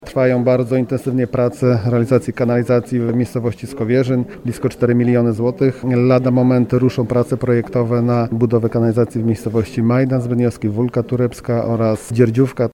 To zarówno budowa sieci jak i jej projektowanie. Mówi wójt Zaleszan Paweł Gardy.